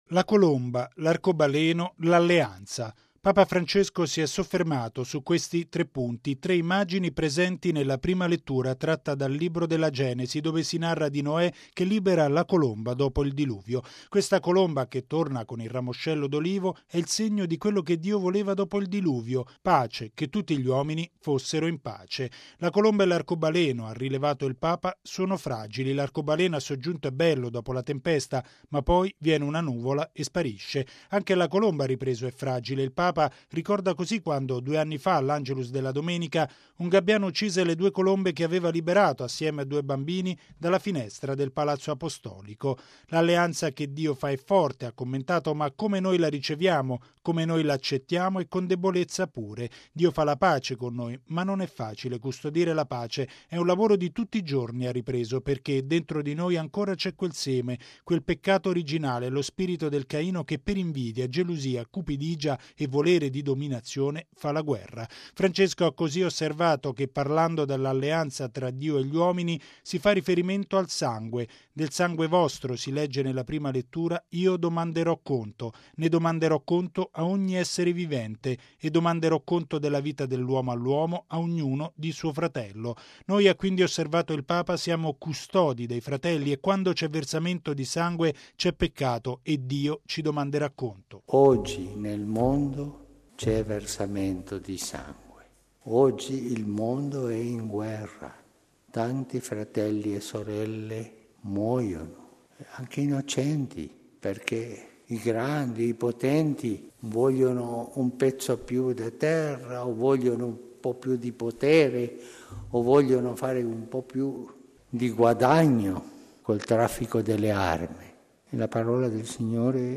La guerra comincia nel cuore dell’uomo, per questo tutti siamo responsabili della custodia della pace. E’ quanto sottolineato da Papa Francesco nella Messa mattutina a Casa Santa Marta.